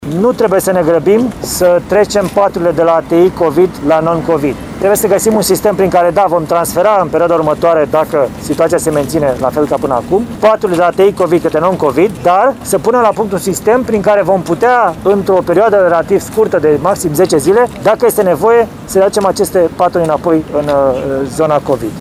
Prezent astăzi la Suceava, la centrul de vaccinare Drive-Thru din parcarea Shopping City, premierul FLORIN CÎȚU a mulțumit cadrelor medicale pentru activitatea depusă în perioada pandemiei, subliniind că devotamentul și puterea de muncă de care au dat dovadă a contribuit la sănătatea populației.